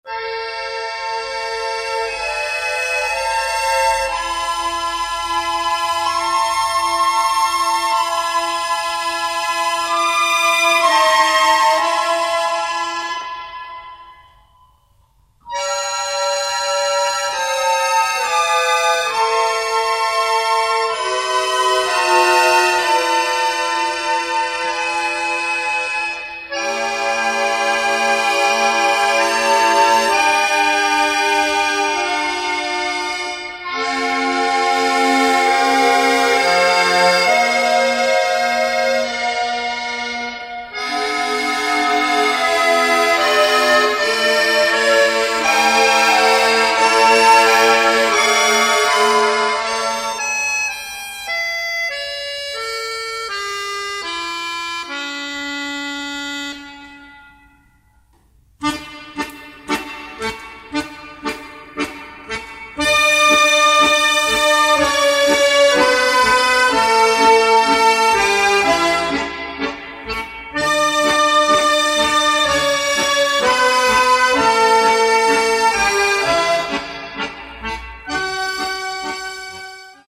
Una fisorchestra di ben 20 elementi affermata in Italia e All'estero.